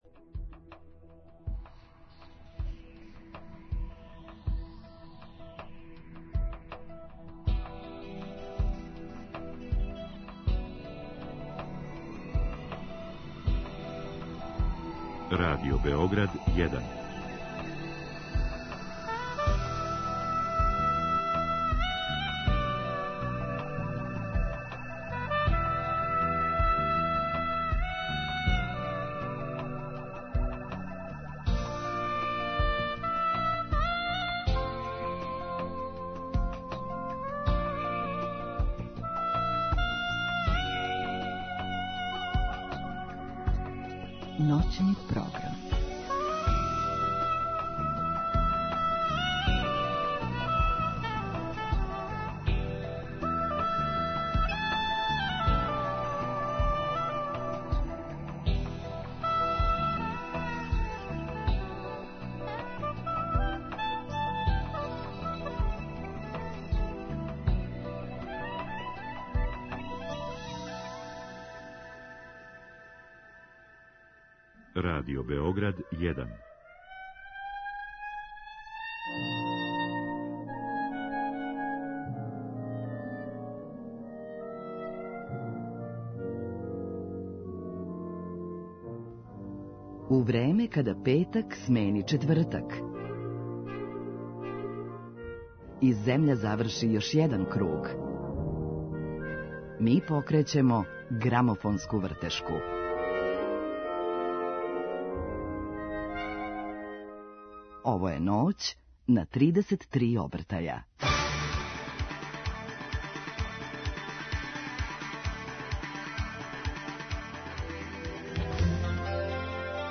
Гости: дуо Калем
Калем је састав који изводи традиционалну музику балканских простора, одевену у ново рухо.